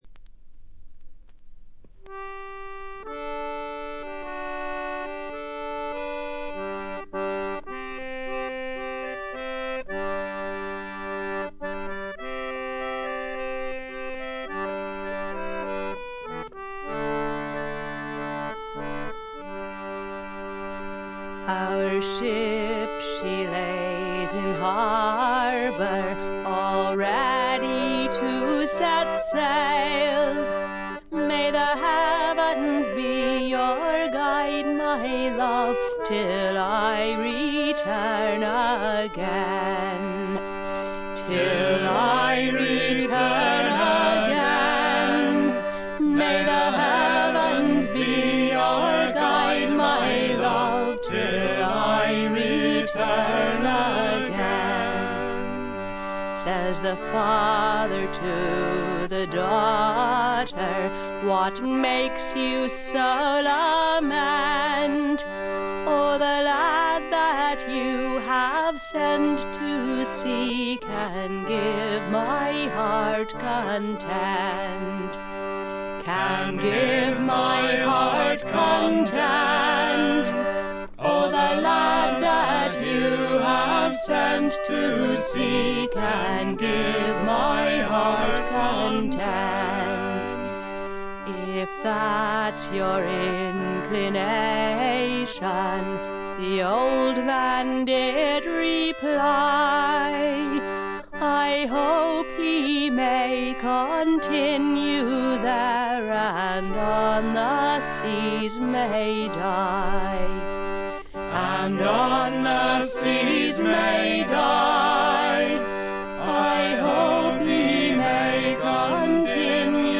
ホーム > レコード：英国 フォーク / トラッド
清澄で凛とした空気感が素晴らしいアルバムです。
インストと歌ものがほぼ交互に現れるスタイル。
試聴曲は現品からの取り込み音源です。
Vocals, Whistle
Vocals, Harp, Harmonium